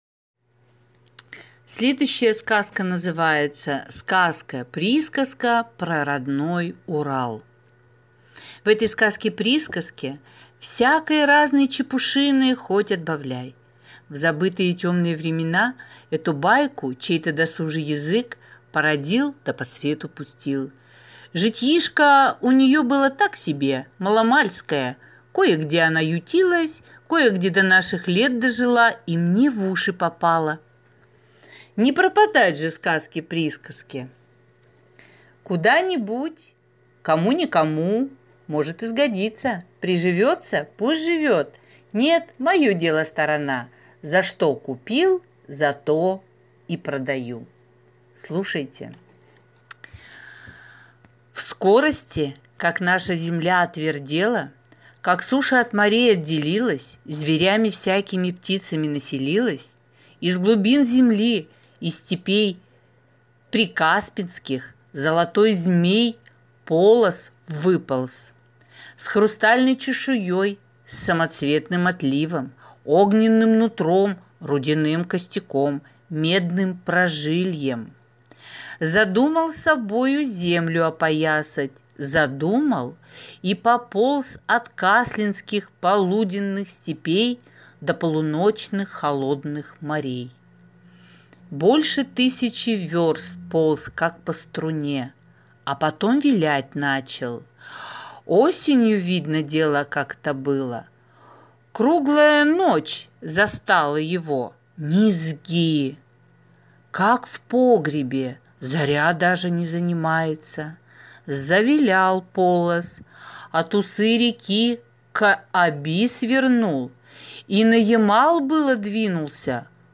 Сказка-присказка про родной Урал (аудиоверсия)